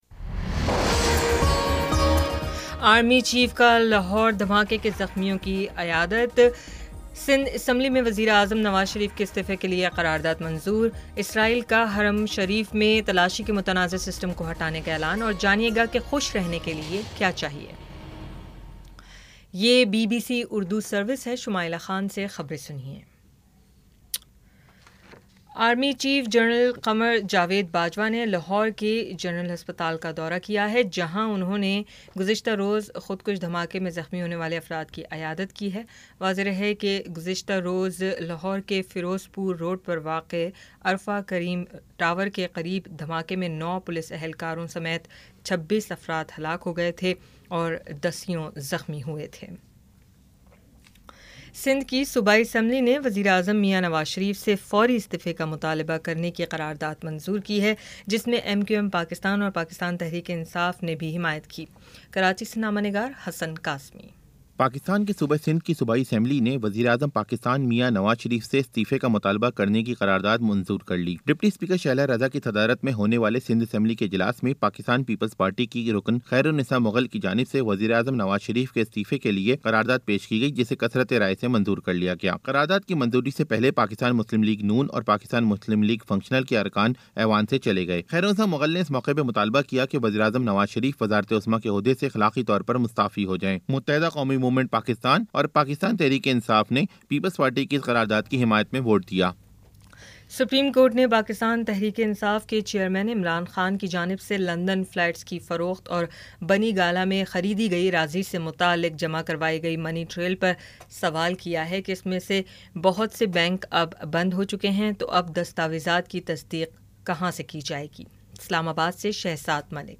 جولائی 25 : شام چھ بجے کا نیوز بُلیٹن